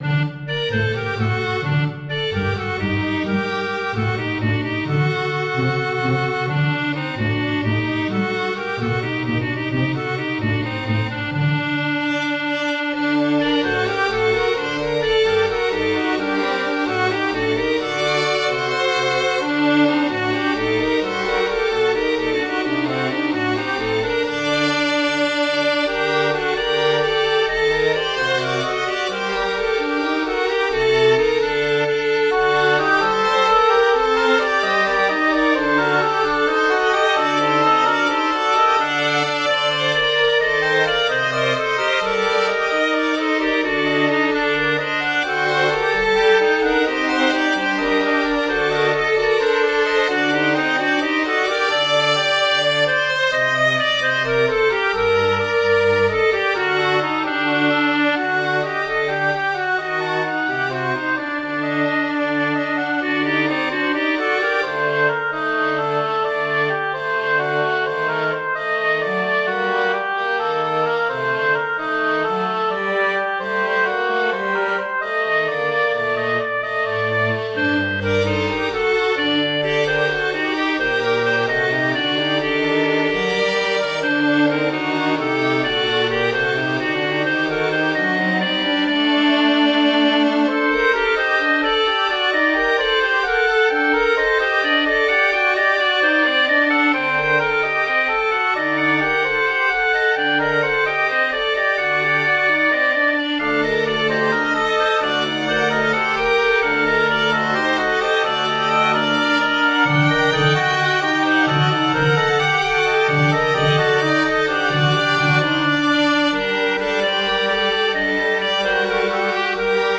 They use themes from Bavaria, Macedonia and other Balkan countries, but modified to fit these works. They are for a small chamber group – two violins, cello, oboe and clarinet.